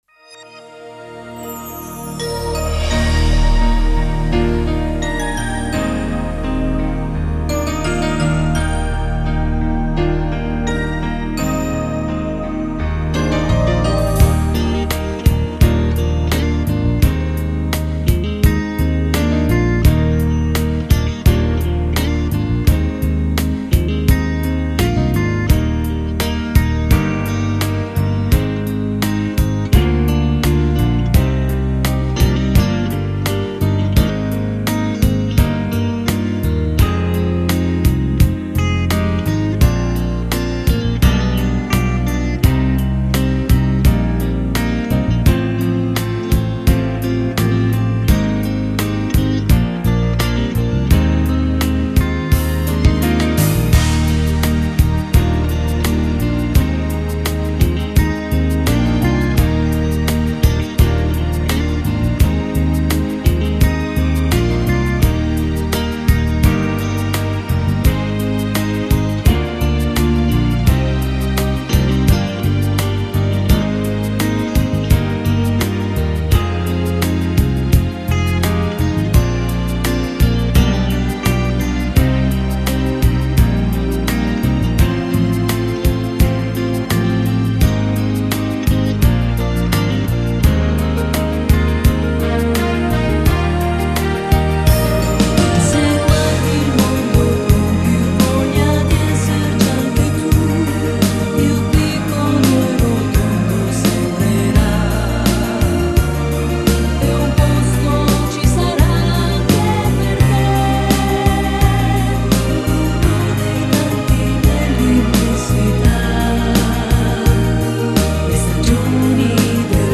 Genere: Lento
Scarica la Base Mp3 (3,80 MB)